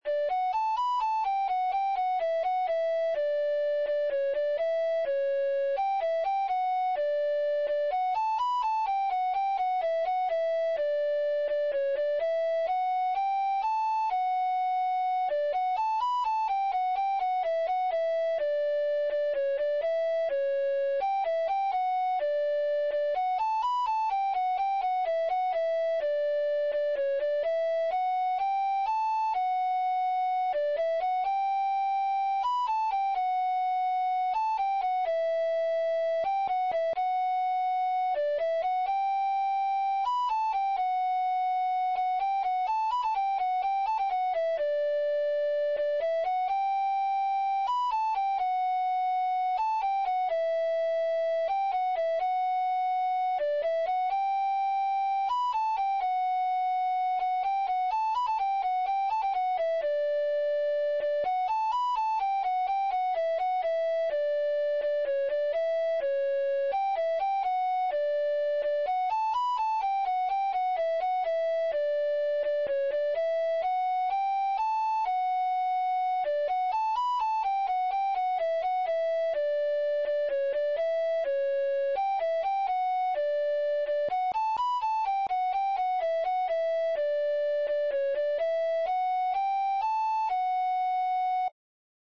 Pasodobres – Páxina 2 – Pezas para Gaita Galega
Pasodobres
Seran uns arquivos mp3. moi sinxelos xerados a partires dun midi, máis para darse unha idea de como soa o tema agardo que sirvan.
Segunda voz.